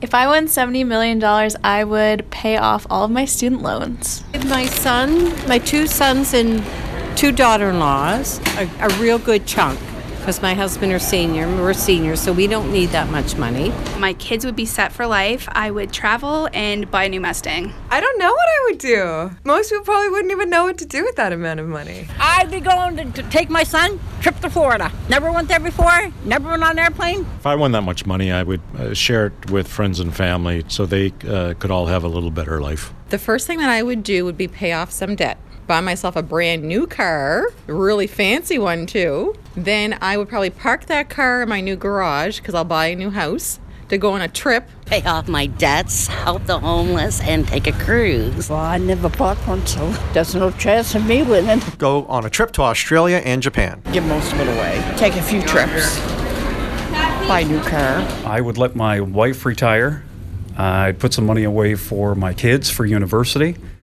We hit the streets to find out what people would do if they won the top prize.